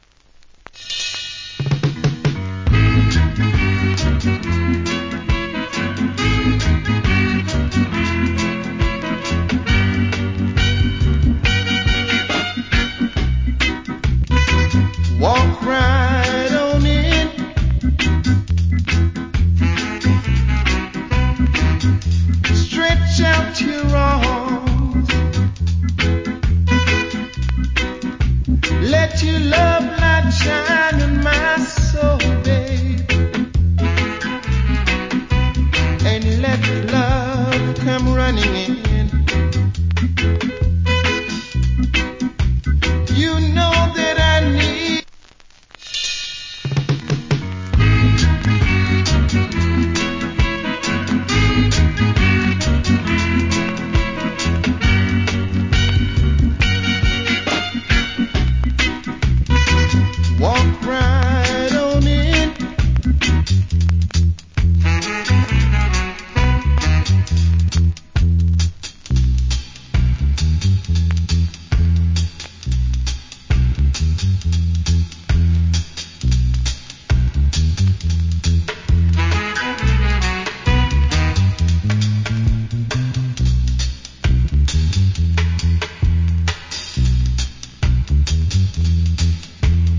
Reggae Vocal